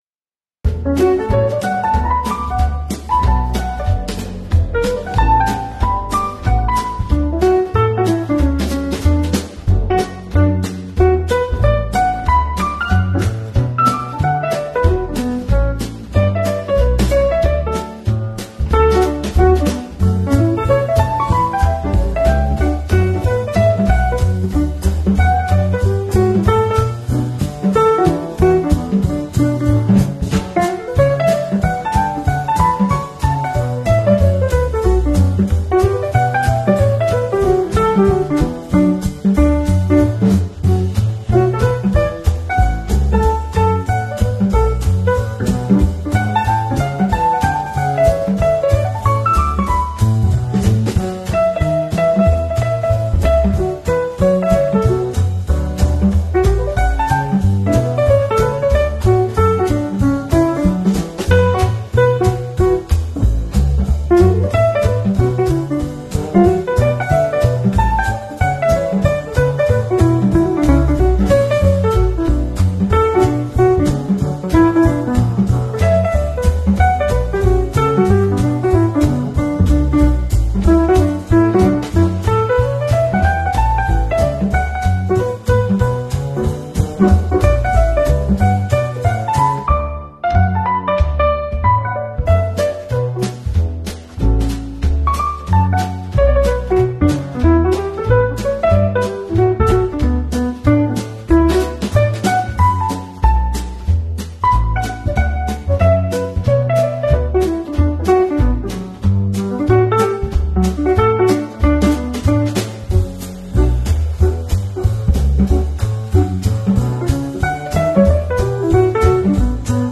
Uplifting Jazz Fusion BGM for Morning Energy